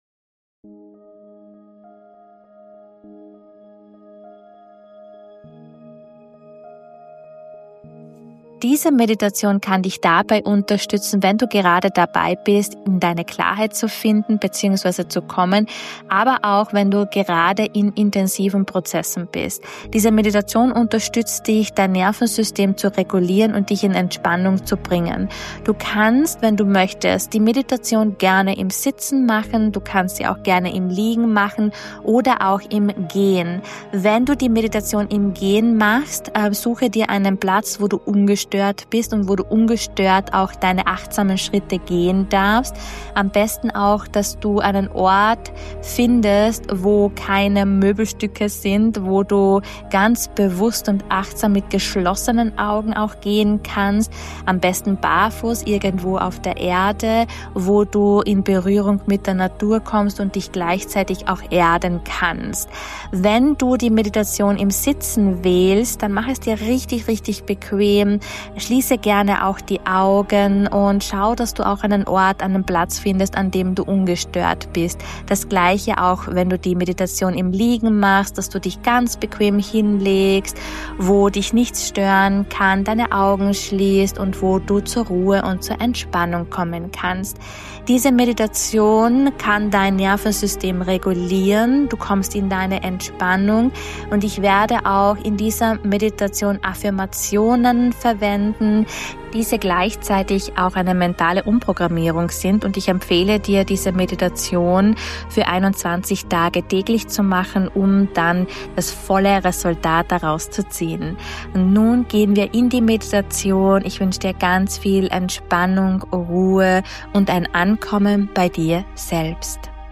080 I Affirmationsmeditation: Ich darf SEIN ~ Intu Soul - Der Podcast
Diese Meditation beinhaltet auch Affirmationen, diese gleichzeitig eine mentale Umprogrammierung sind, um dir selbst zu erlauben, dein authentisches Sein wahrzunehmen, egal in welchen Prozessen du gerade bist.